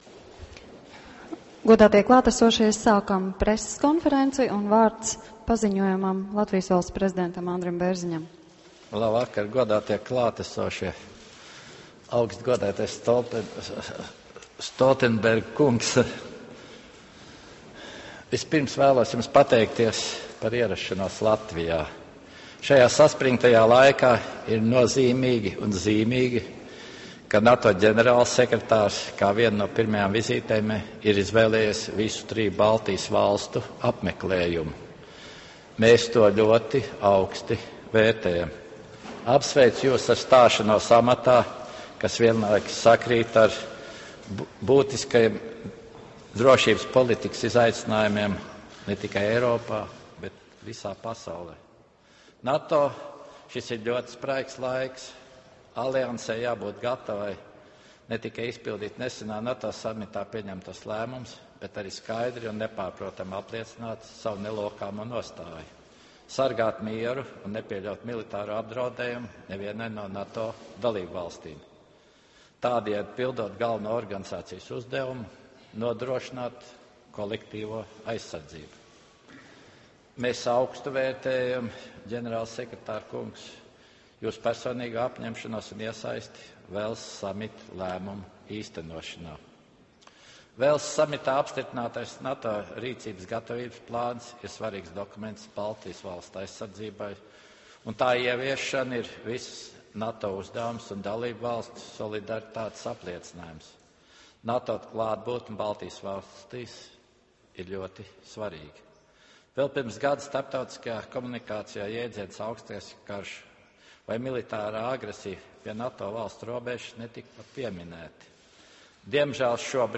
ENG - Joint press conference with NATO Secretary General Jens Stoltenberg and Latvian President Andris Bērziņš 20 Nov. 2014 | download mp3 ORIG - Joint press conference with NATO Secretary General Jens Stoltenberg and Latvian President Andris Bērziņš 20 Nov. 2014 | download mp3